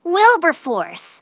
synthetic-wakewords
ovos-tts-plugin-deepponies_Scootaloo_en.wav